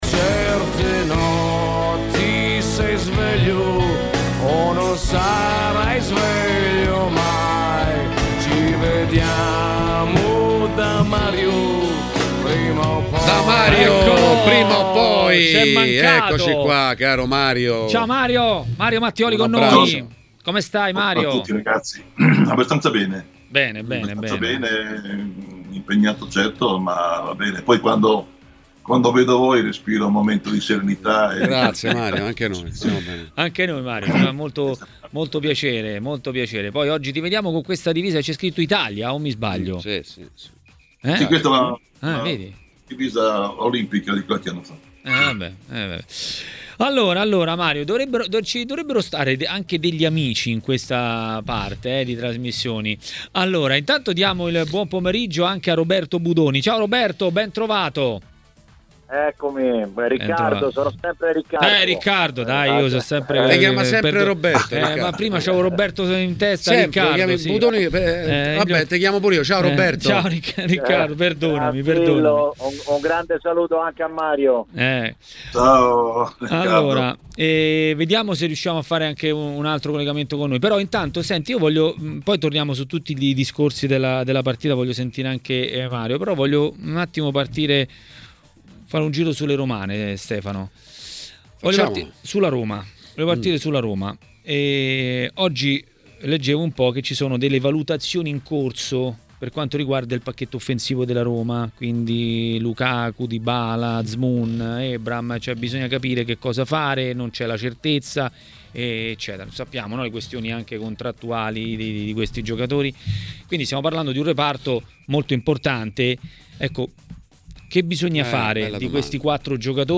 Durante Maracanà, trasmissione di TMW Radio, è intervenuto l'ex calciatore e tecnico Ciccio Graziani. Queste le sue parole sulla Roma: